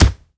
punch_general_body_impact_04.wav